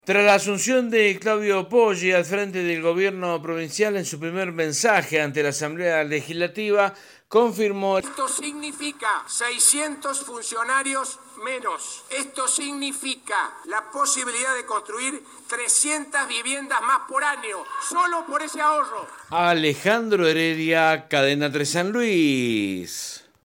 En su primer discurso como gobernador electo de la provincia de San LuisClaudio Poggi anunció que rebajará el plantel en un 40%.